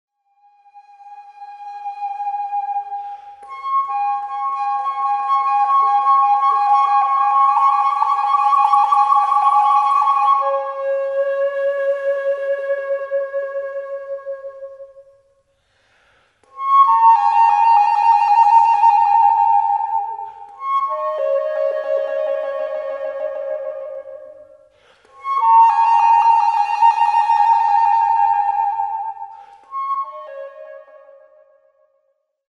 Baroque, Cross-cultural